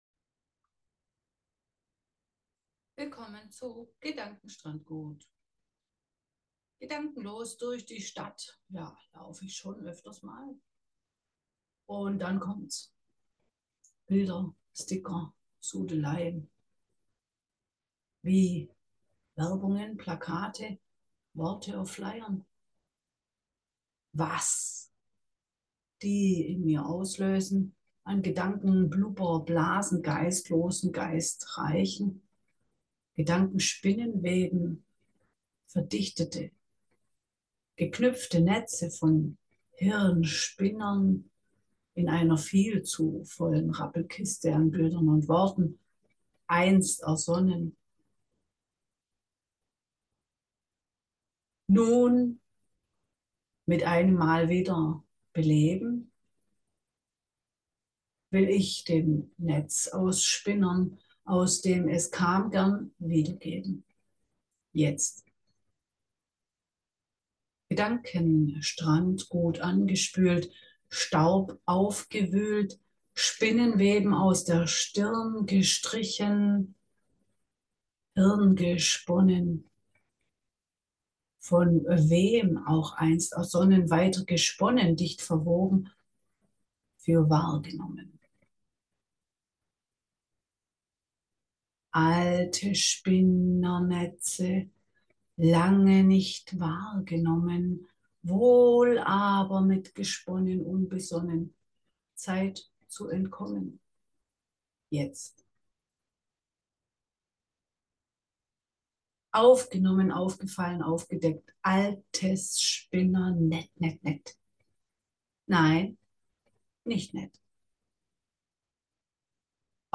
Hörbeitrag